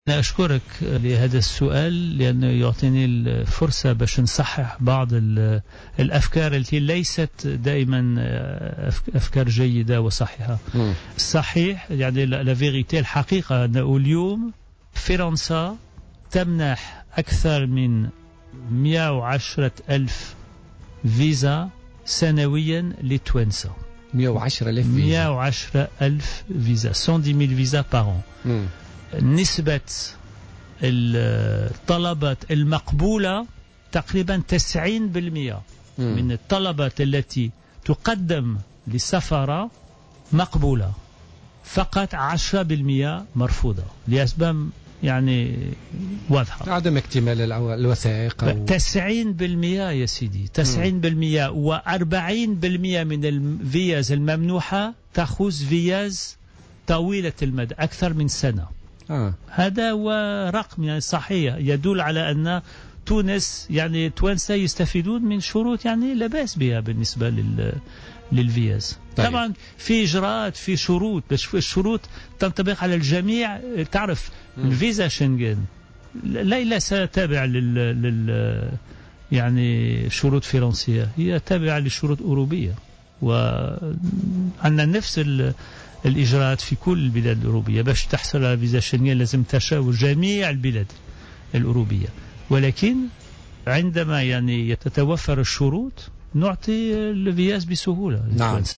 وأوضح غويات، في حوار خاص مع الجوهرة أف أم، بث اليوم الخميس، أن 40 % من التأشيرات الممنوحة طويلة الأمد وتتيح لأصحابها الإقامة في فرنسا لمدة سنة أو أكثر، كما اعتبر السفير أن الأرقام تثبت استفادة التونسيين من شروط "لا بأس بها" للحصول على فيزا شينغن على حد تعبيره.